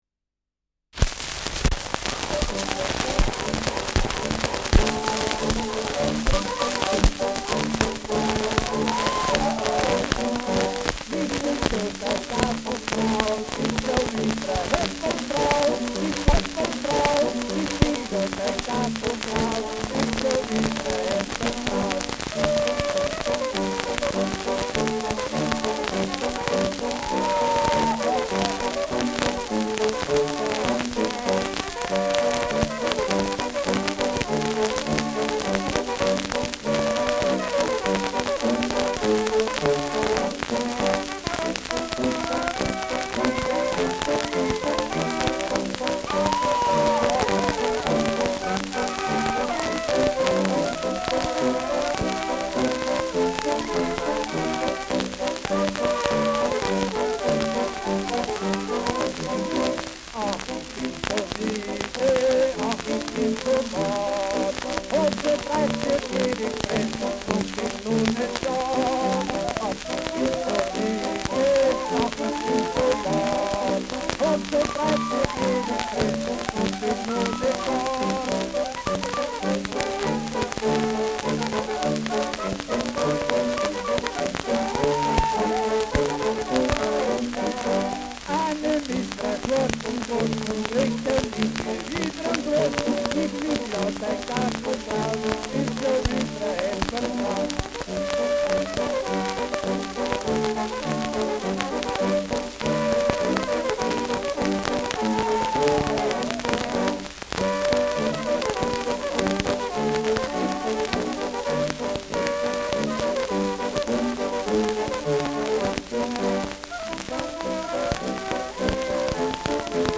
Geißbock-Schottisch II : mit Gesang
Schellackplatte
Starkes Grundrauschen : Durchgehend leichtes bis starkes Knacken : Springt im ersten Drittel
Kapelle Die Alten, Alfeld (Interpretation)